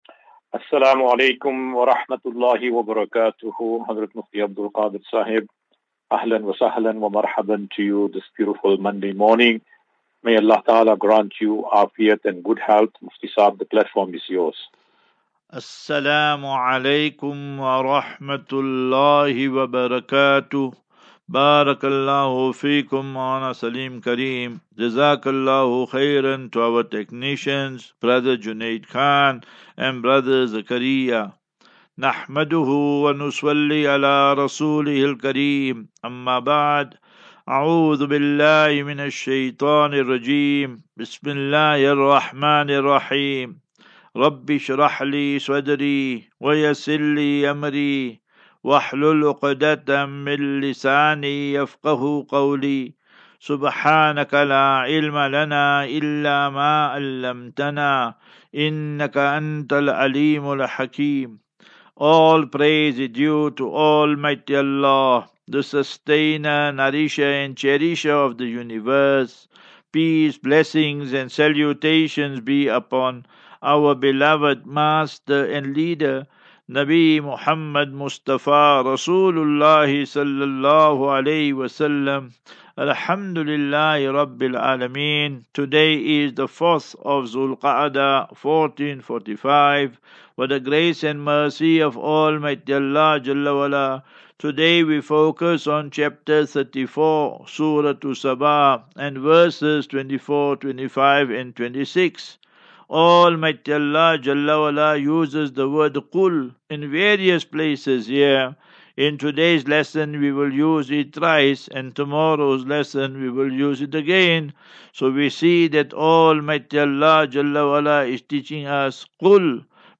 As Safinatu Ilal Jannah Naseeha and Q and A 13 May 13 May 2024.